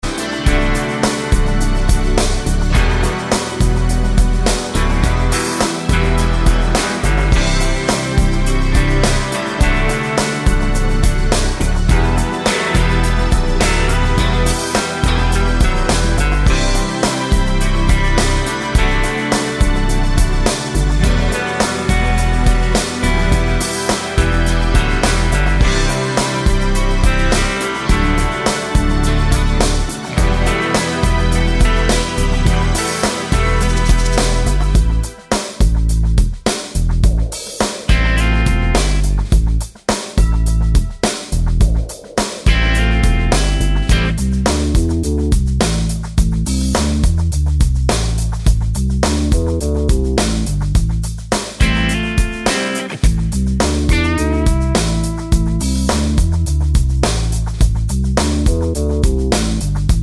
no Backing Vocals Rock 4:31 Buy £1.50